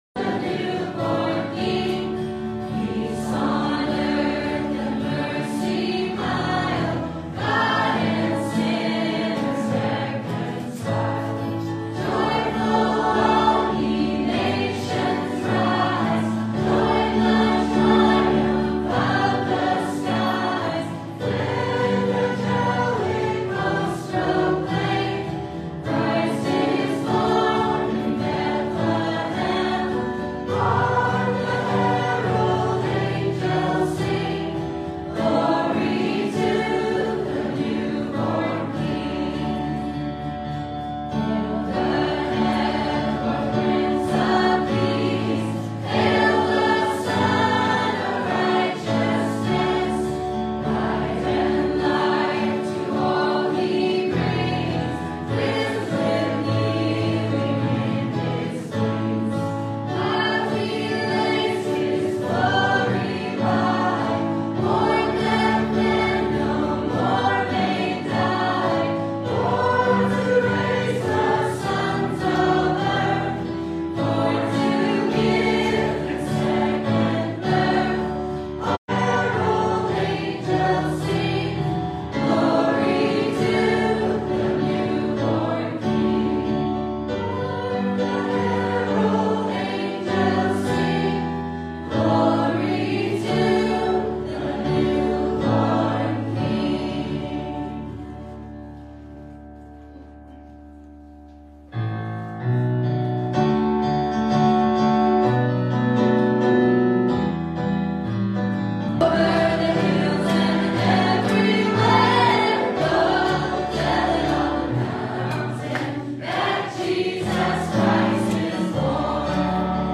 Christmas Eve Service